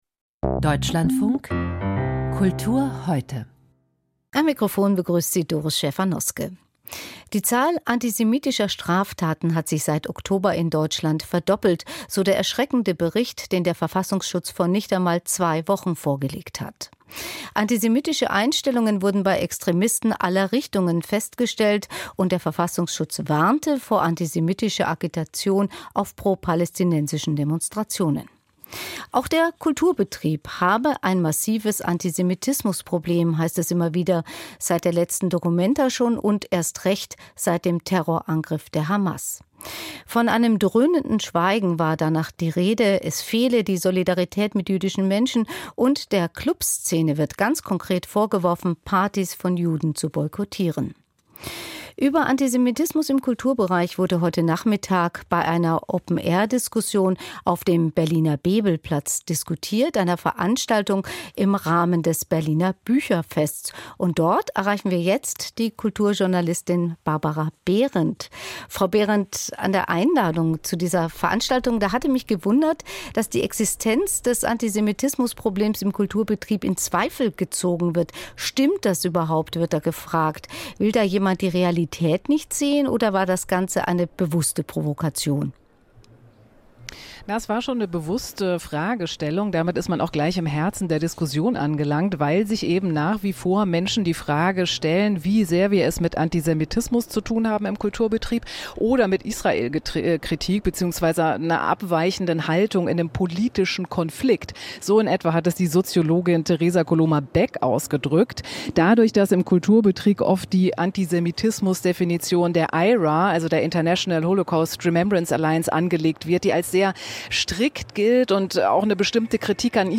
Antisemitismus im Kulturbetrieb? Podiumsdiskussion beim Berliner Bücherfest